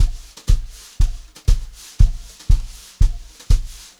120JZBEAT5-L.wav